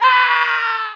YELL1.mp3